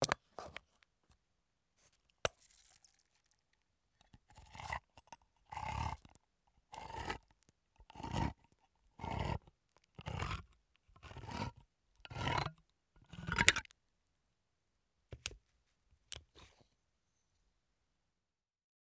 Can Opener Manual Open Lid Sound
household
Can Opener Manual Open Lid